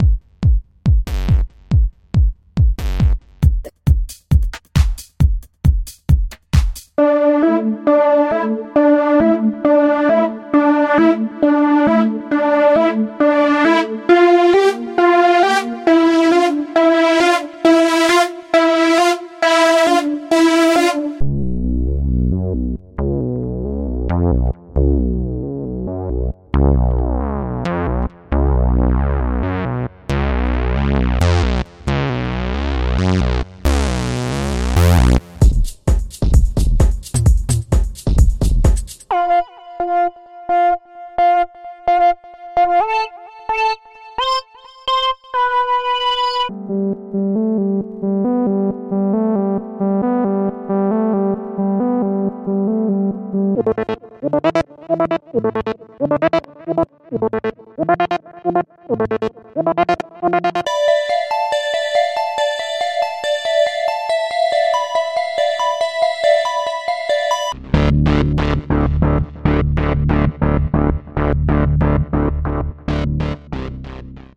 Download Loops and Samples 135-140 Bpm